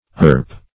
Irp \Irp\, Irpe \Irpe\ ([~e]rp), n. [Etymol. uncertain.]